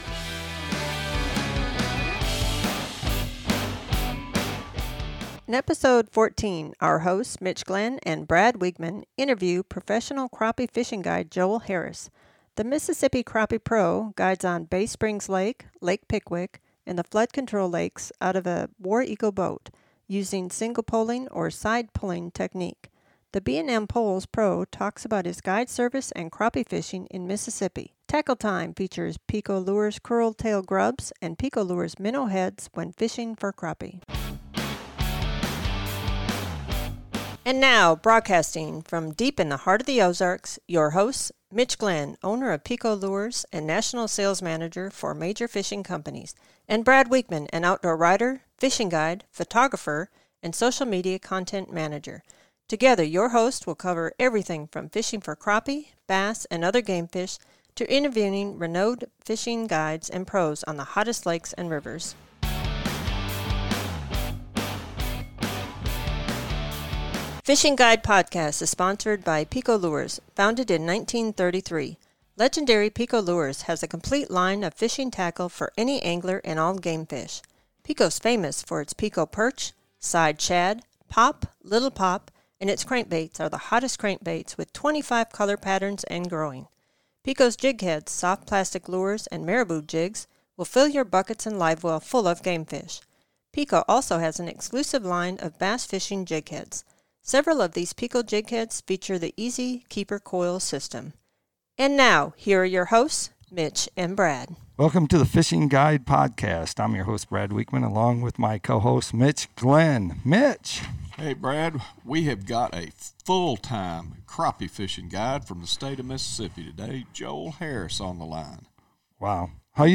interview professional crappie fishing guide